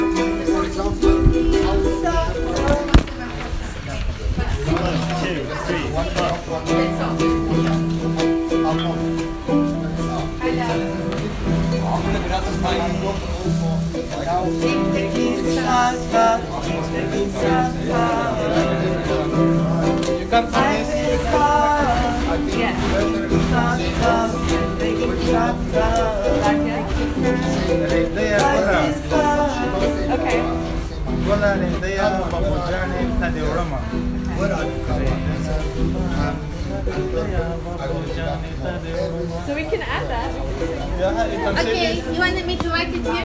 There is room for musical instruments to solo as well, particularly as the song has a kind of jazzy, blues feel to it.
The song is a chanting of the Dari phrase Zindgi Sakhta, which means Life is hard.
These are recordings from the center that show the process of writing the song:
life-is-hard-repeat-this-line.m4a